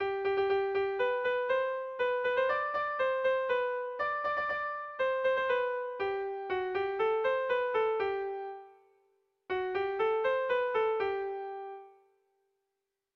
Sehaskakoa
ABDE